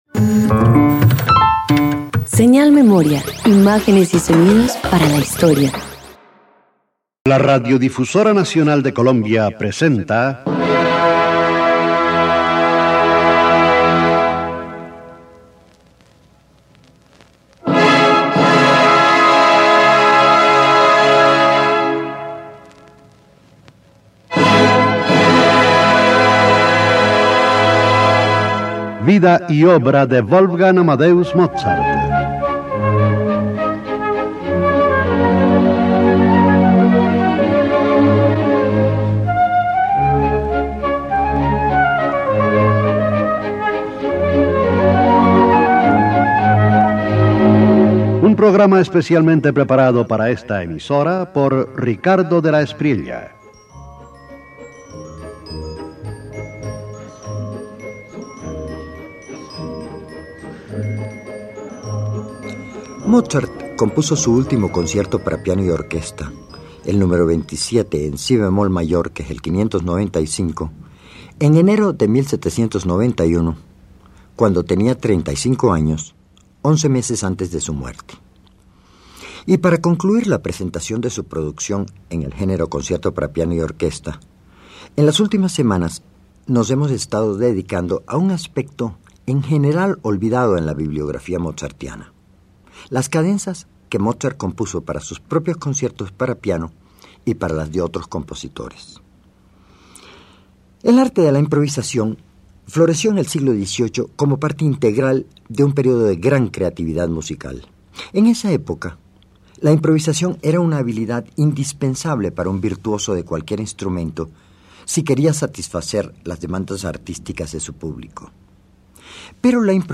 322 Cadenzas para piano y orquesta Parte III_1.mp3